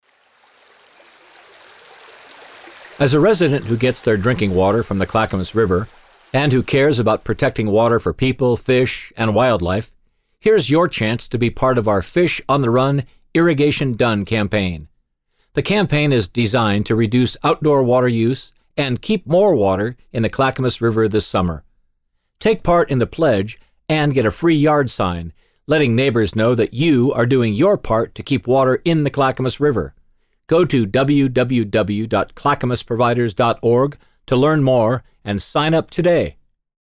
The audio PSAs (Public Service Announcements) below are designed to inform and educate our customers on ways to be more efficient with their outdoor water use throughout the summer and how to turn down and shut off outdoor watering in the late summer in time for the fall fish migration in the Clackamas River.